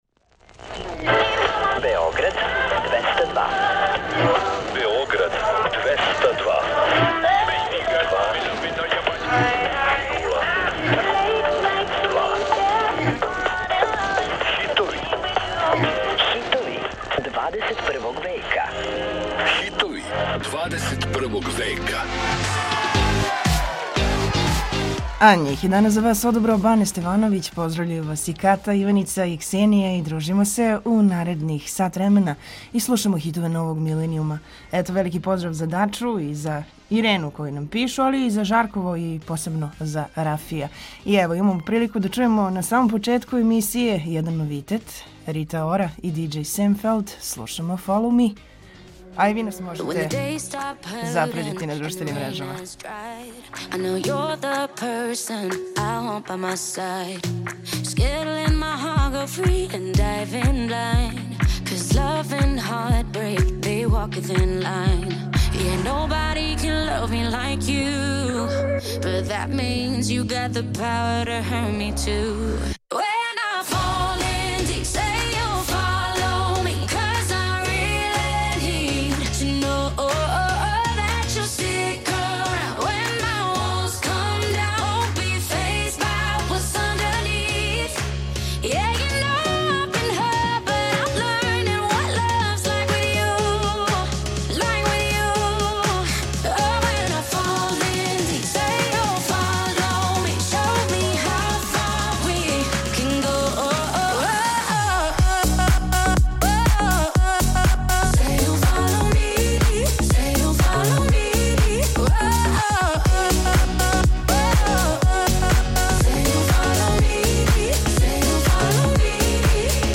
Slušamo hitove novog milenijuma, koje osvajaju top liste i radijske stanice širom planete.